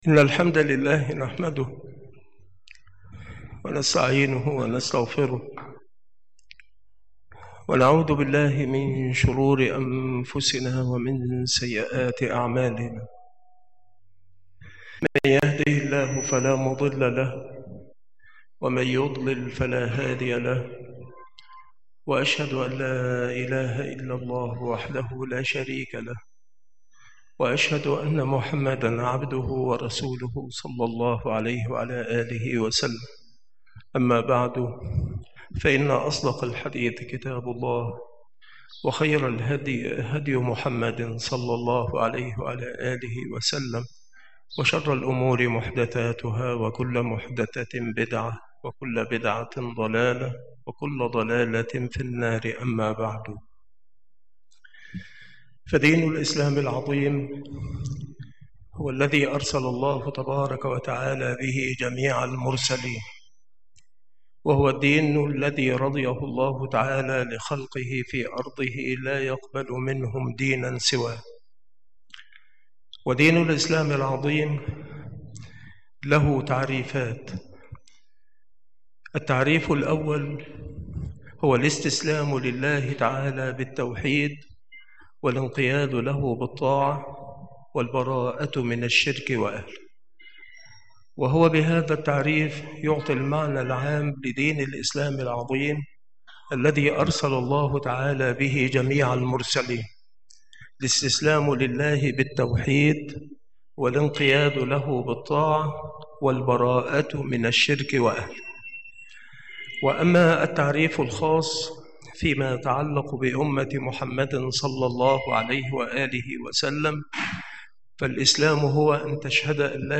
المواعظ الجامعة
مكان إلقاء هذه المحاضرة بالمسجد الشرقي - سبك الأحد - أشمون - محافظة المنوفية - مصر